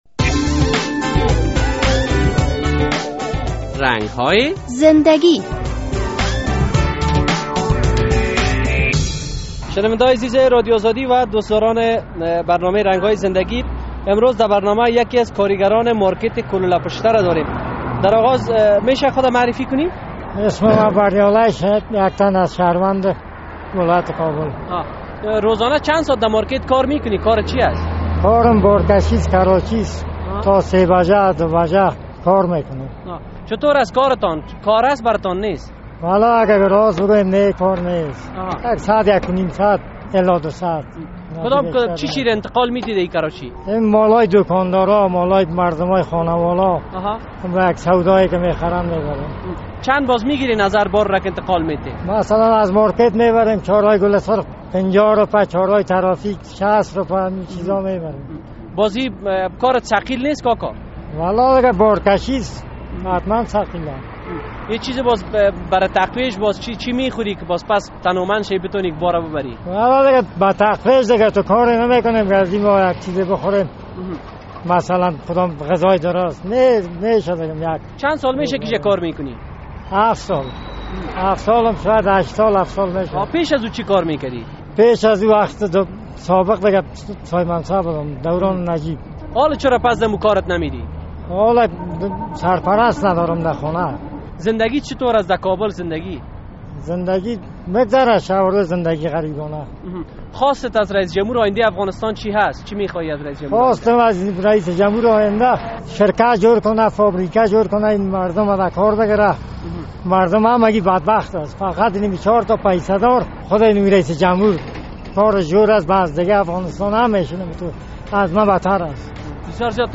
در این برنامهء رنگ های زندگی با یک تن از کاری گران مارکیت کلوله پشته صحبت شده است.